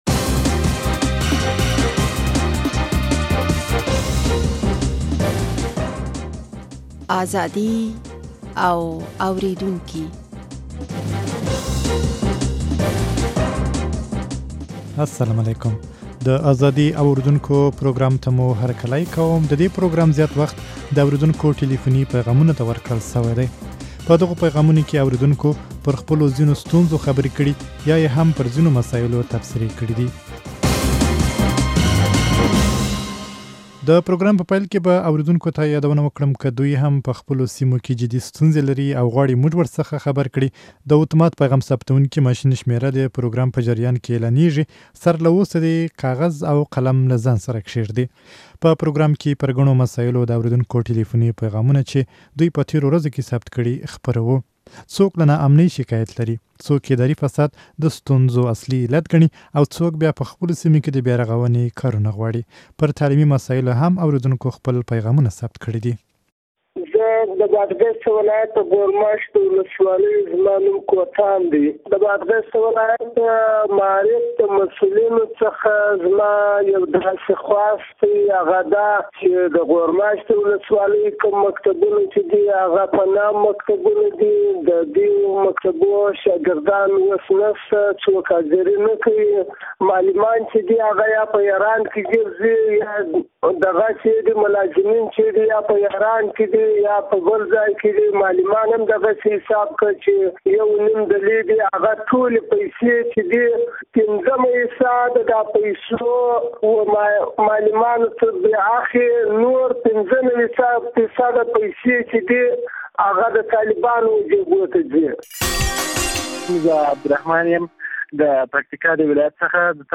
د دې پروګرام زيات وخت د اورېدونکو ټليفوني پيغامونه ته ورکړل شوى دى. په دغو پيغامونو کې اورېدونکو پر خپلو ځينو ستونزو خبرې کړي يا هم يې پر ځينو مسايلو خپلې تبصرې کړې دي.